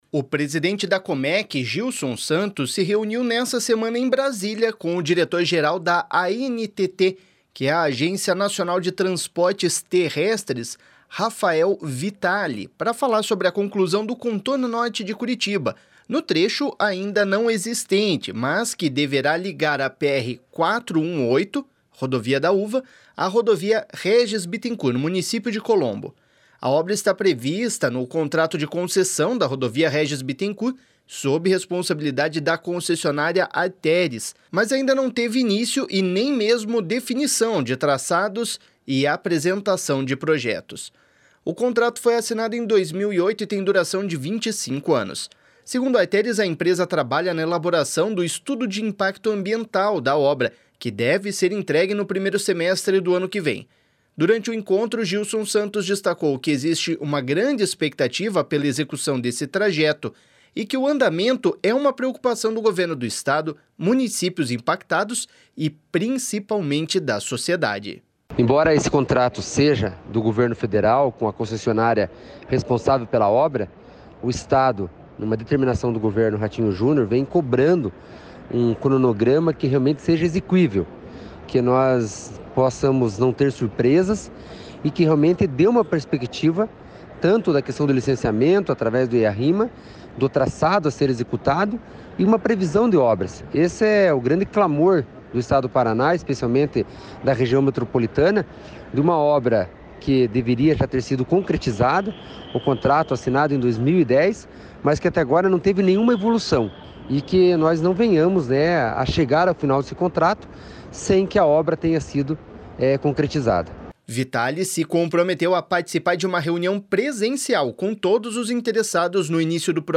//SONORA GILSON SANTOS//